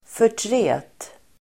Uttal: [för_tr'e:t]